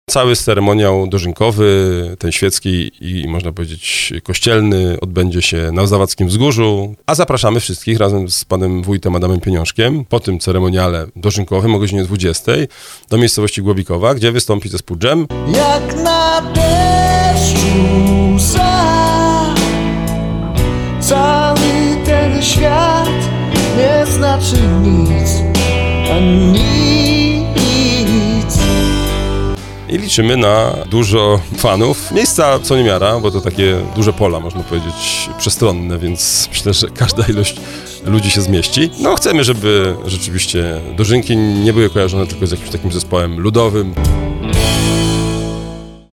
Będzie czas na modlitwę, tradycyjne obrzędy, wspólne świętowanie i zabawę – zaprasza starosta powiatu dębickiego Piotr Chęciek.